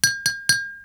clinking.wav